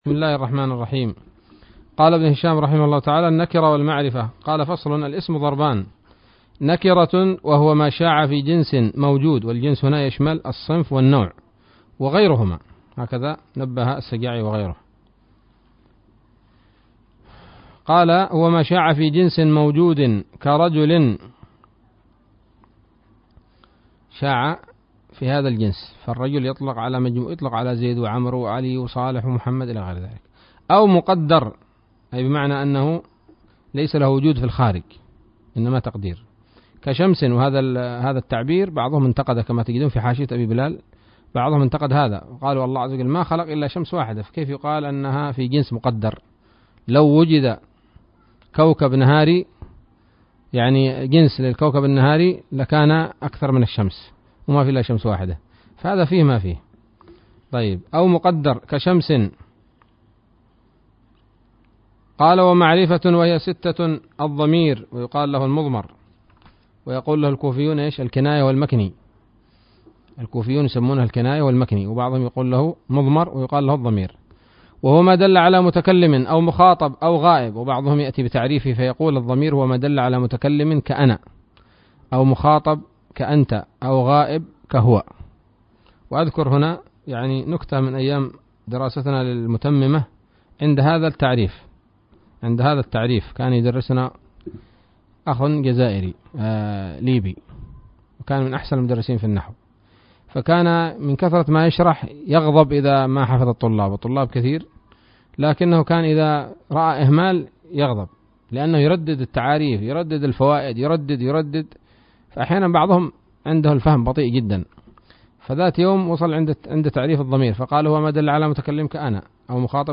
الدرس الأربعون من شرح قطر الندى وبل الصدى